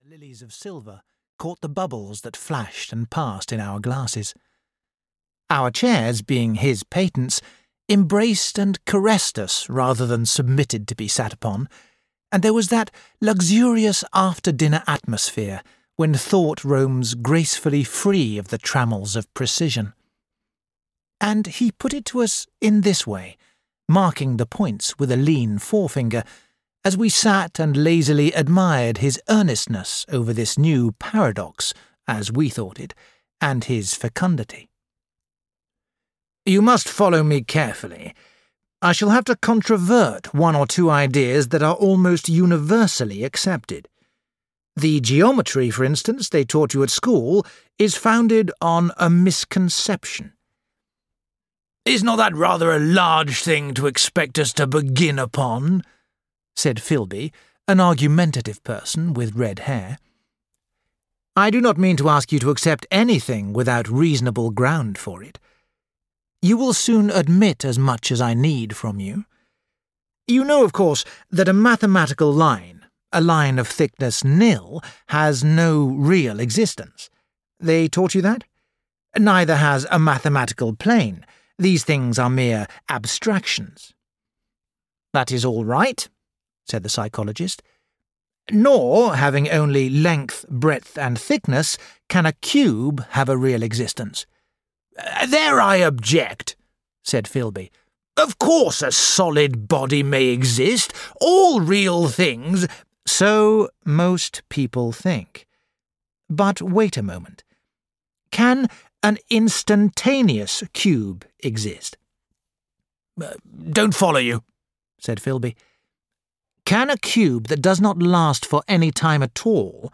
The Time Machine (EN) audiokniha
Audiobook The Time Machine written by H. G. Wells. At a dinner party in Richmond, the host tells an astonishing story of his travels into the future.
Ukázka z knihy